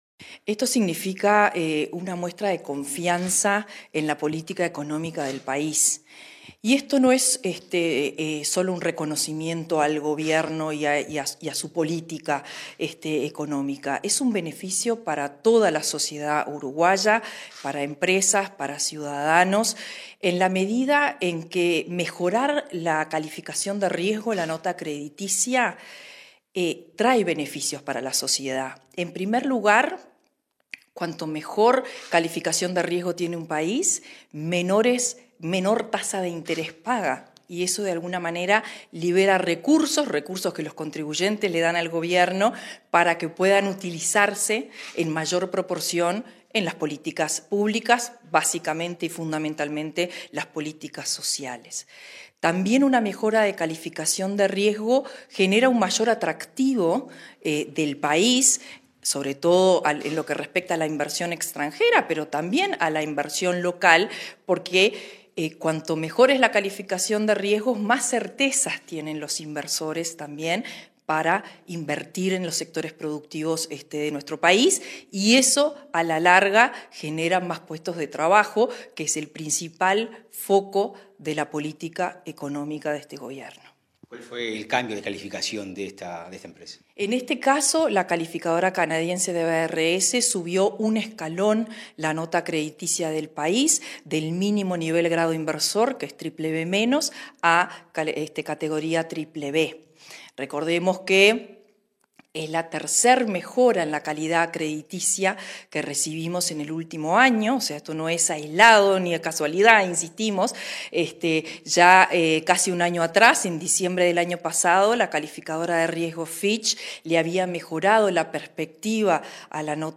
Entrevista a la directora de Política Económica del MEF, Marcela Bensión